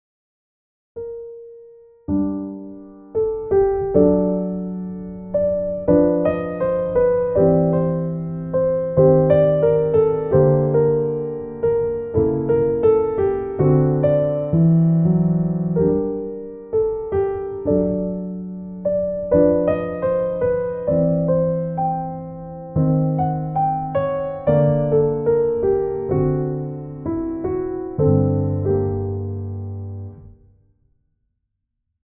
ブログ、ト短調１.mp3